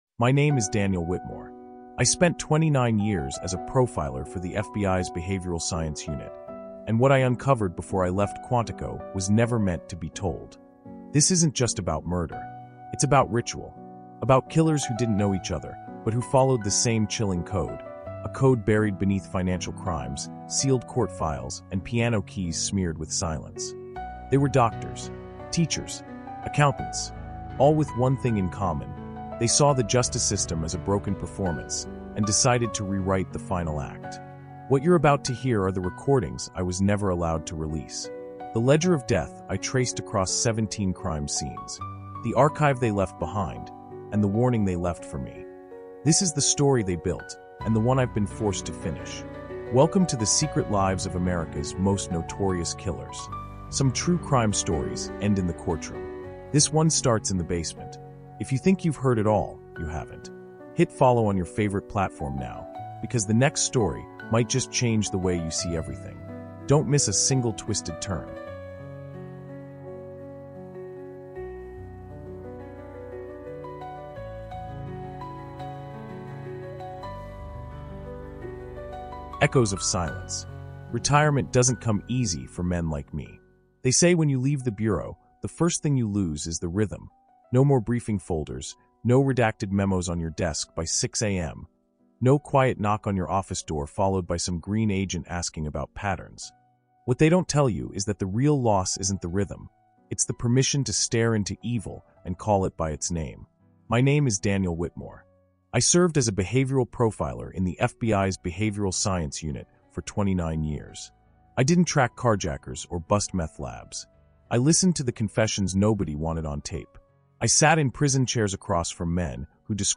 TRUE CRIME meets conspiracy in this immersive first-person thriller, narrated